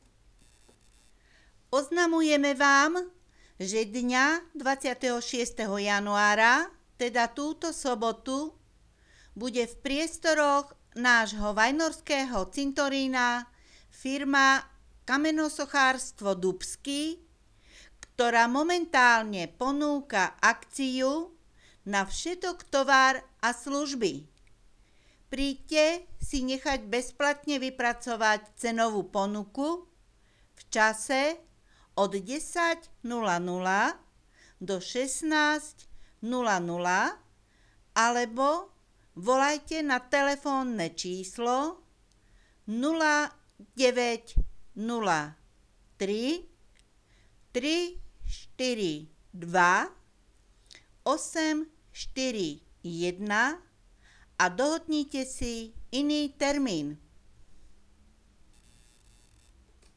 kamenarstvo_dubsky_komercne_hlasenie.wav